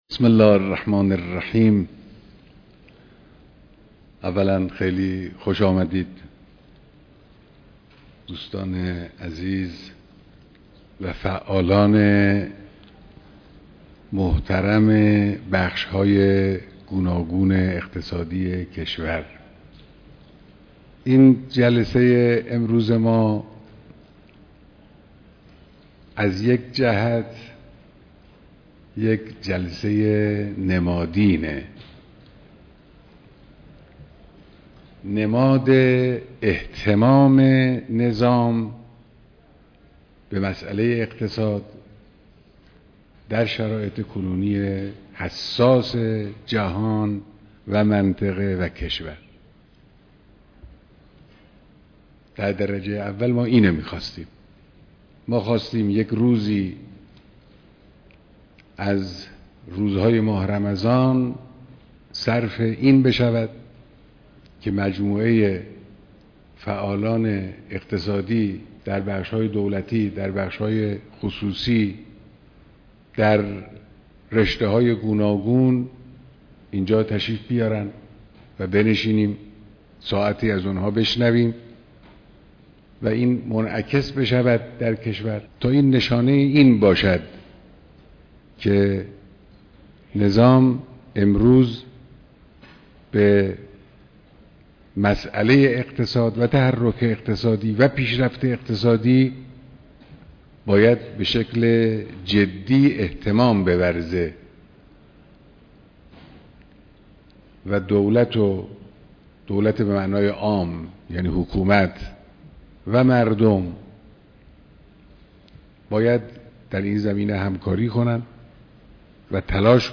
بيانات در ديدار فعالان و برگزيدگان بخش‌هاى اقتصادى‌